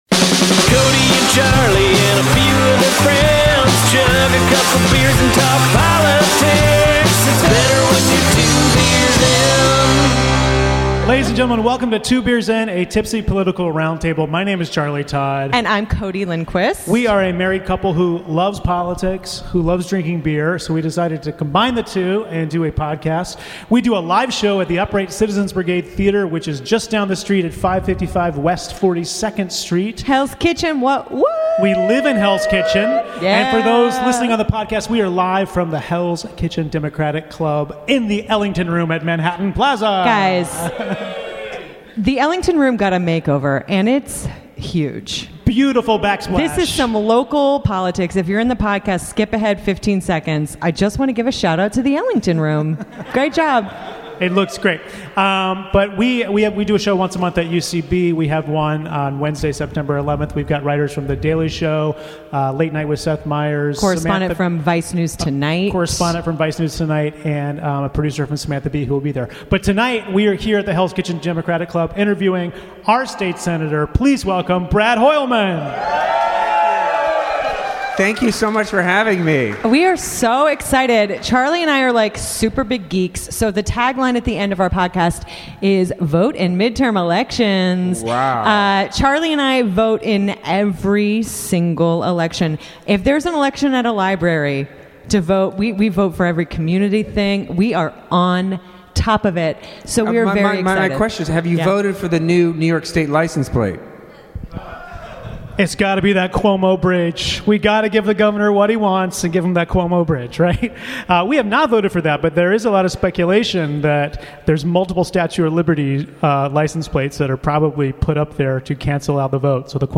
We get tipsy with our State Senator, Brad Hoylman at the Hell's Kitchen Democratic Club.